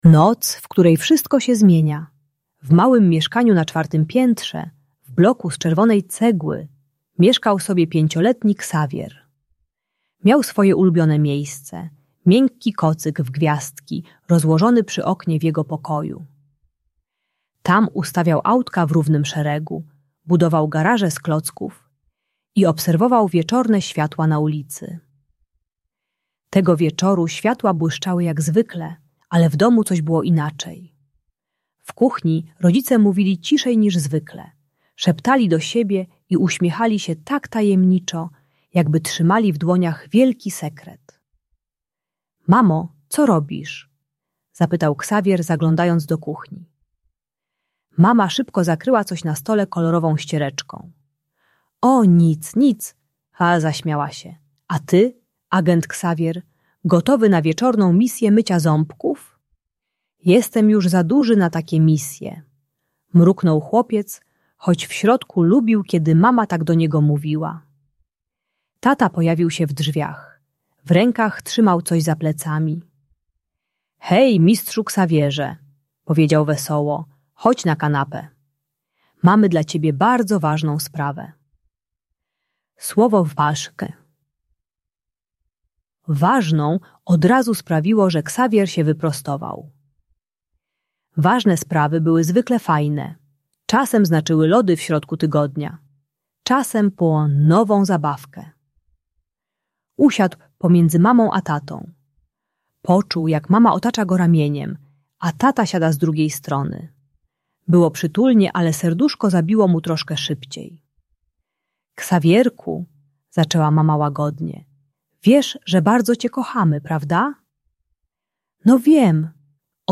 Noc, w której wszystko się zmienia - Rodzeństwo | Audiobajka